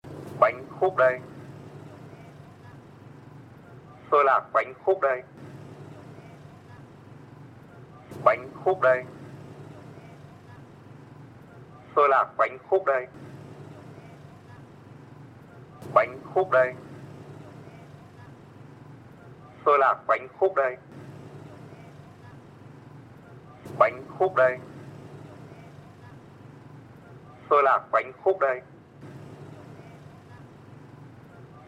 Tiếng rao Bánh Khúc Đây, Xôi Lạc Bánh Khúc Đây…
Description: Tiếng rao “Bánh khúc đây!”, “Xôi lạc bánh khúc nóng đây!” vang lên ngân dài, nhịp nhàng, khi thì kéo âm cao, lúc hạ xuống trầm ấm. Âm giọng rao, hô, mời, gọi len lỏi qua từng ngõ phố, hòa vào không khí sớm mai. Tiếng xe đạp, xe máy hoặc gánh hàng khẽ kẽo kẹt, xen cùng tiếng bước chân, tạo nên bối cảnh đời thường đặc trưng của phố phường Việt Nam.
tieng-rao-banh-khuc-day-xoi-lac-banh-khuc-day-www_tiengdong_com.mp3